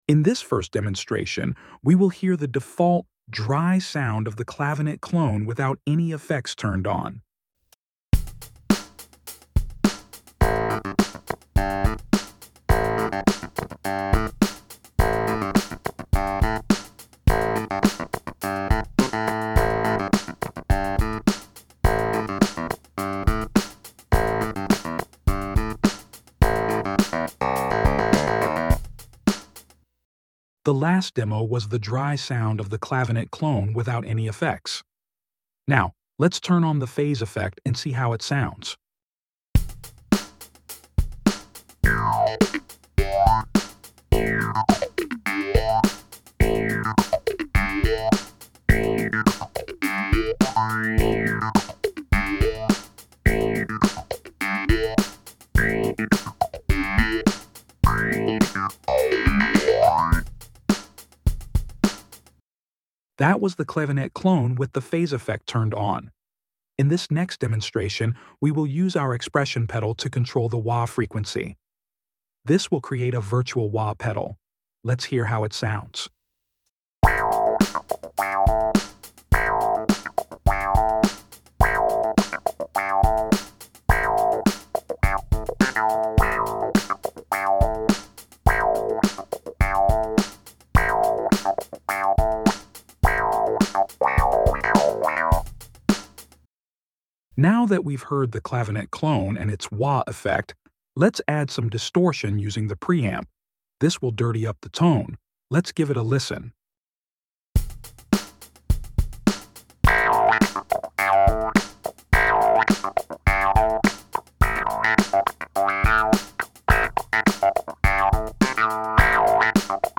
Ultra-realistic vintage clavinet
• Virtual stringed clavinet built with 18,500 high-resolution samples
A revolutionary sound like no other, Clavinet Clone faithfully recreates the iconic sound of a real stringed clavinet.
Clavinet_Clone_Effects_Demo.mp3